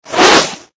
General_throw_miss.ogg